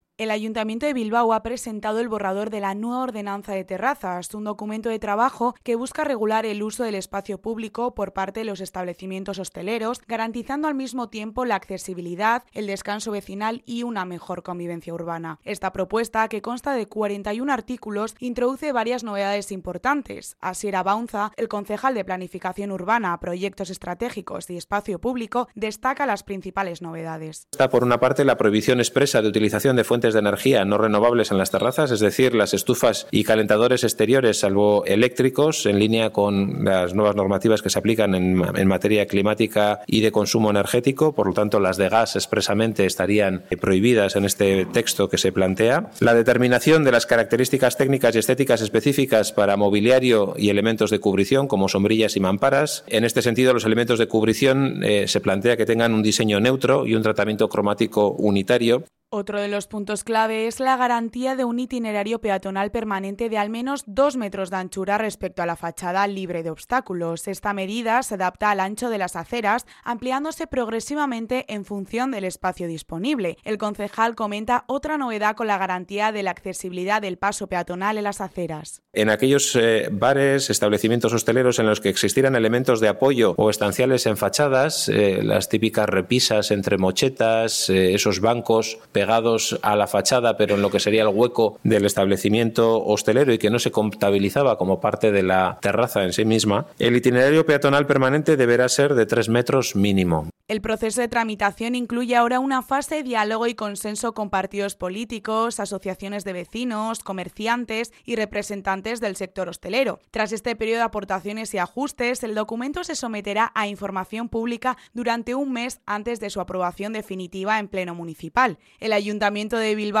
Crónica sobre la nueva ordenanza de terrazas
Rueda de prensa de la nueva ordenanza de terrazas en el Ayuntamiento de Bilbao / Radio Popular- Herri Irratia